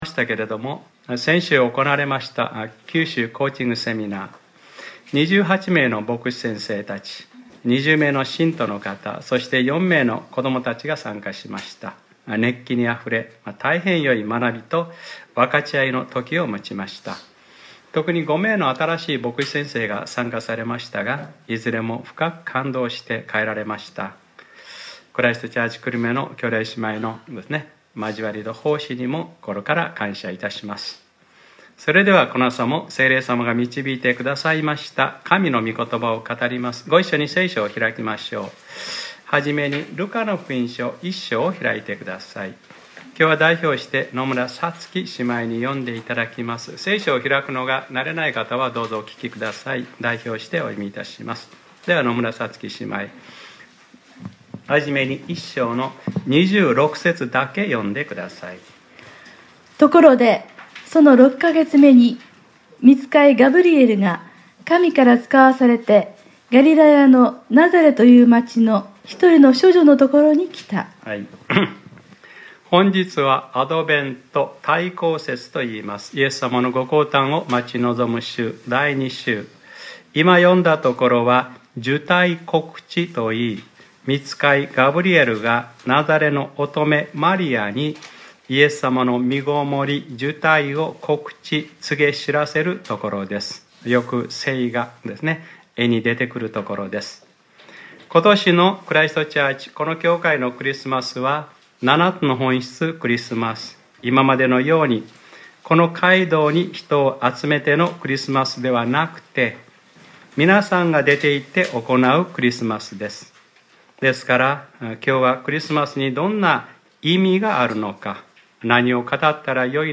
2017年12月 3日（日）礼拝説教『生まれた者は聖なる者、神の子と呼ばれる』